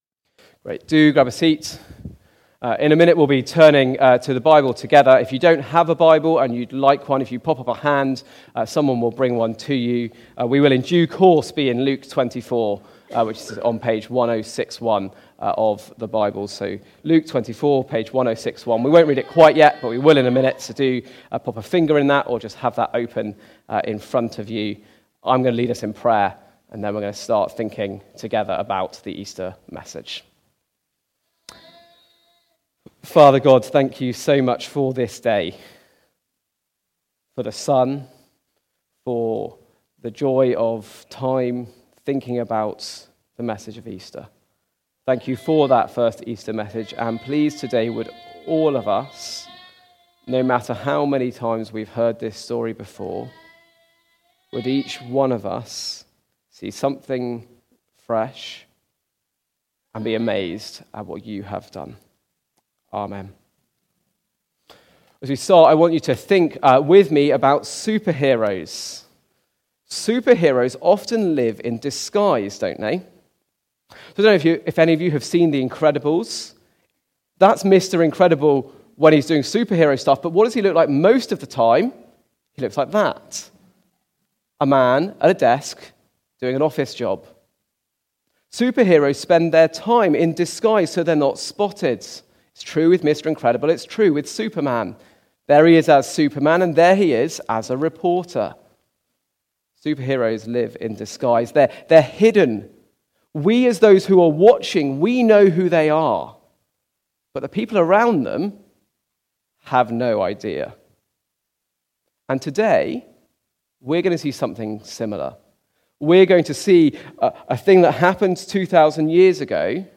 Hope at Easter (Luke 24:13-35) from the series Easter 2025. Recorded at Woodstock Road Baptist Church on 20 April 2025.